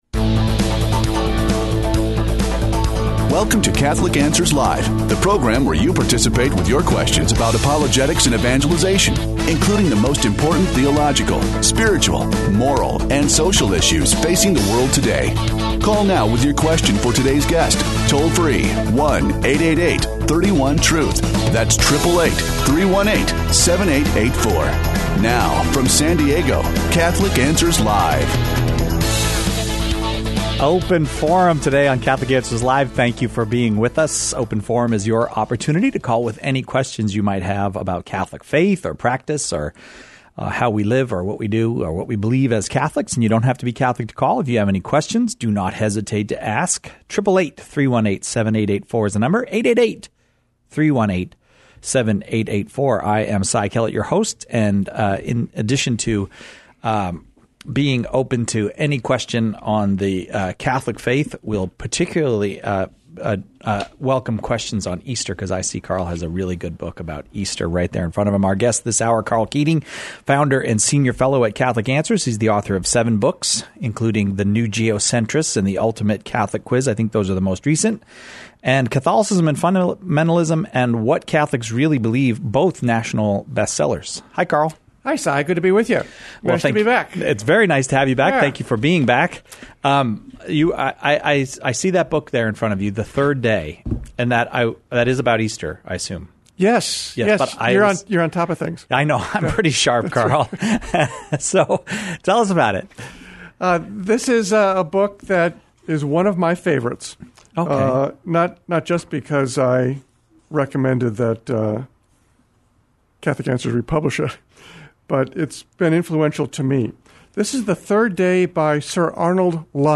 The callers choose the topics during Open Forum, peppering our guests with questions on every aspect of Catholic life and faith, the moral life, and even philos...